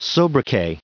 added pronounciation and merriam webster audio
1023_sobriquet.ogg